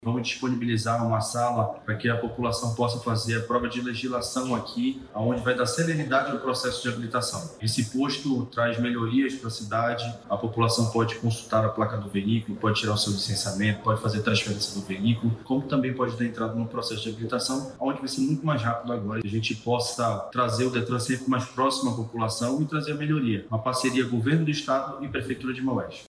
O diretor-presidente do Detran Amazonas, David Fernandes, explica sobre os serviços que vão ser ofertados na nova unidade do município.
SONORA-1-POSTO-DETRAN-MAUES-.mp3